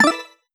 button-play-select.wav